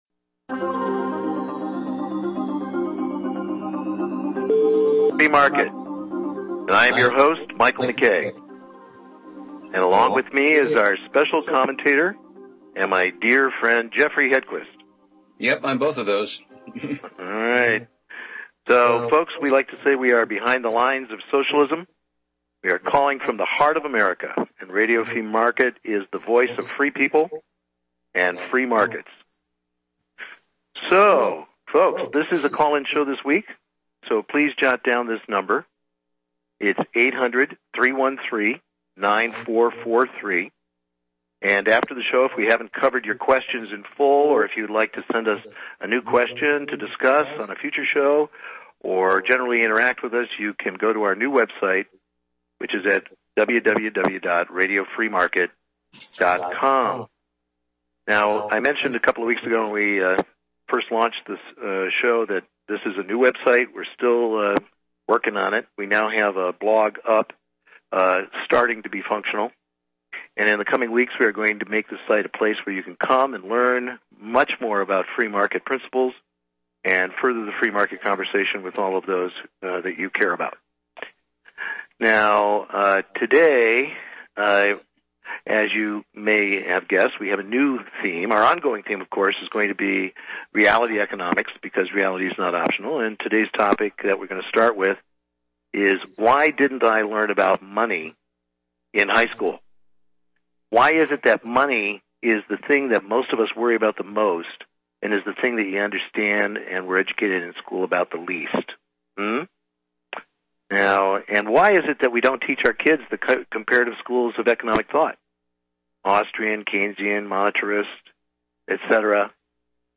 Important comments and questions came in from callers in MN, TN and NC on the importance of REAL Education, our Rights as Americans, and the First Principles which are the foundation of our Liberties.